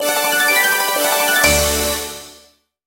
Звуки выигрыша, победы
На этой странице собраны энергичные звуки победы и выигрыша — от фанфар до коротких мелодичных оповещений.